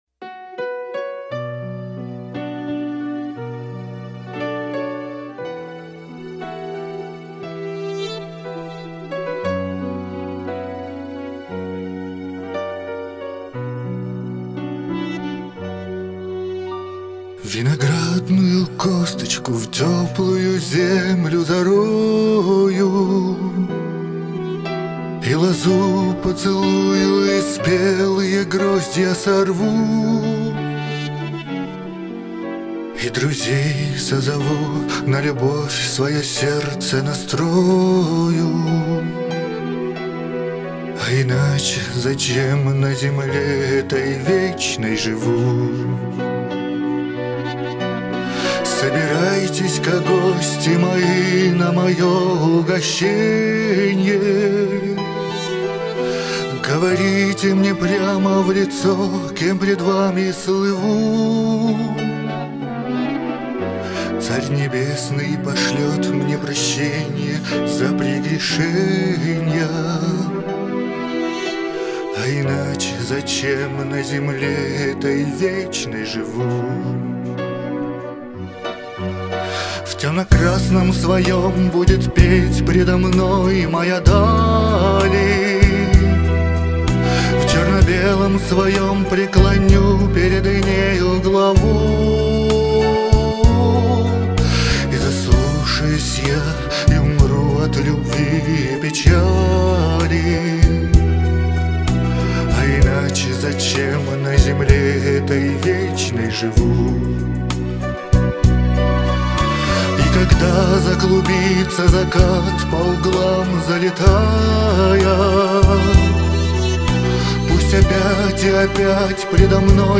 вы поёте правильно, у вас классическое звучание.